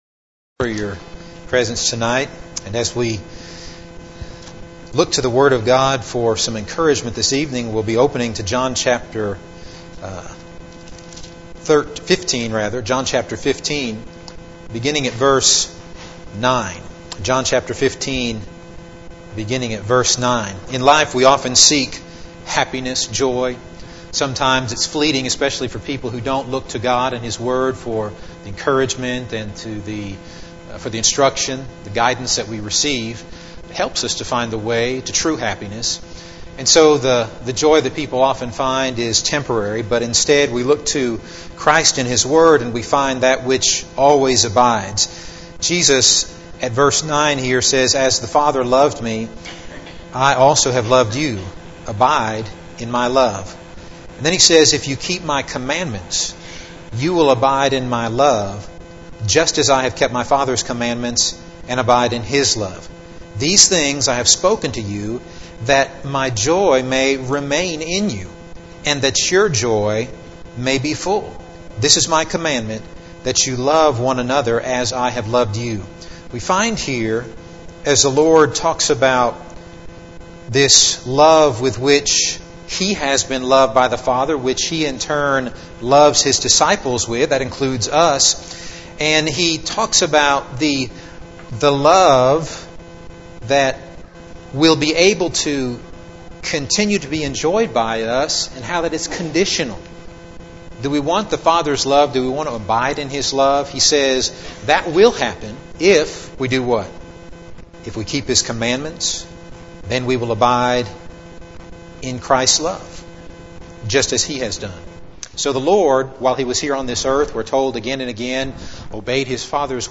Midweek Bible Class « Overcoming Attacks on our Faith Christian Parenting